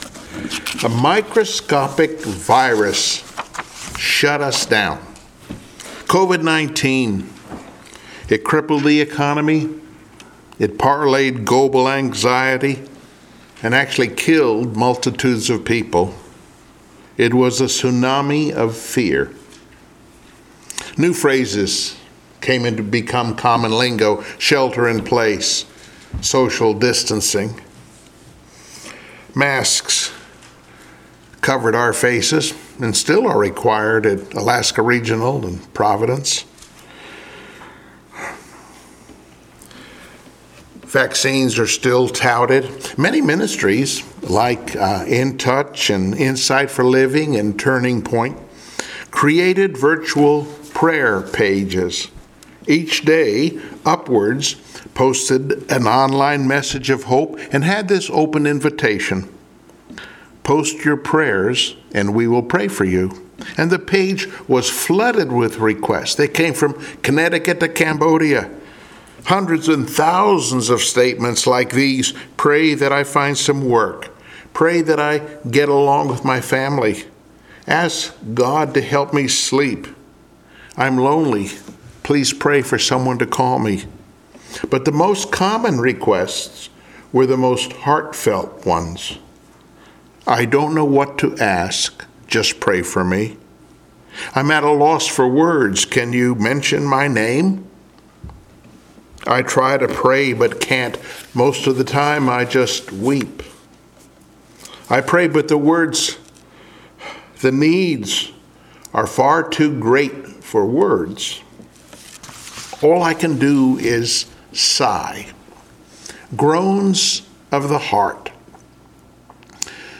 The Holy Spirit Passage: Romans 8:22-28 Service Type: Sunday Morning Worship In the same way